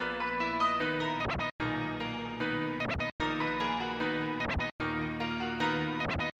黑暗钢琴
Tag: 150 bpm Trap Loops Piano Loops 1.08 MB wav Key : Unknown